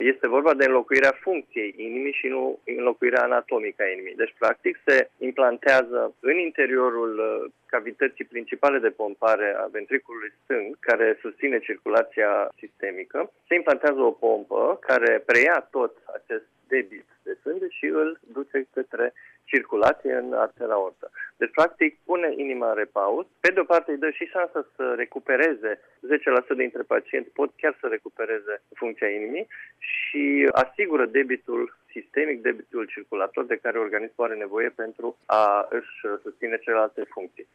Invitat la Radio România Actualități